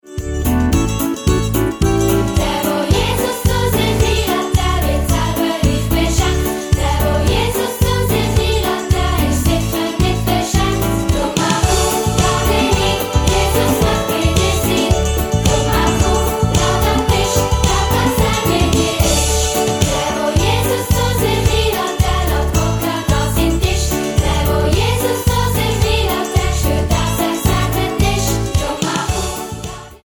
Musical-Album